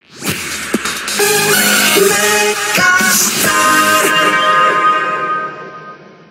Indicatiu de la ràdio